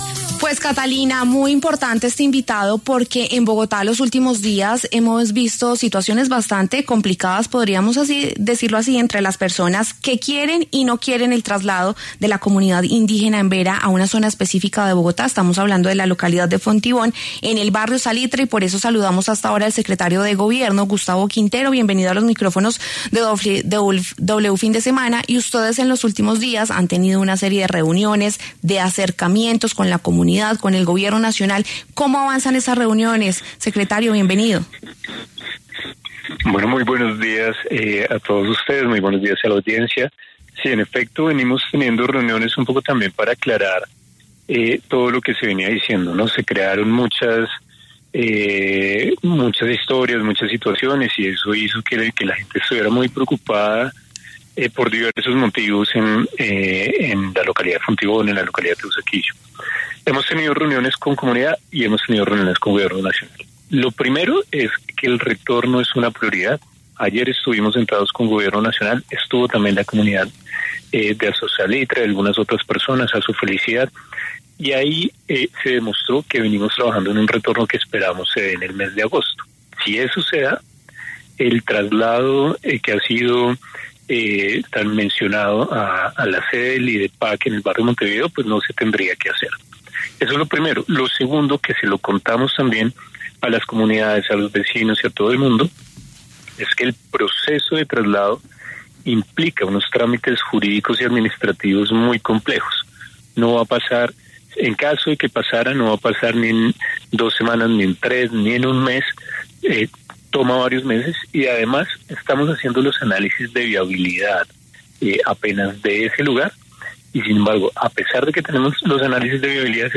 Ante esto, el secretario de Gobierno, Gustavo Quintero, habló en W Fin de Semana y detalló que se han venido teniendo reuniones con la comunidad y el Gobierno para aclarar todas las situaciones que se han creado en torno a este traslado.